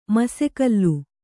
♪ masekallu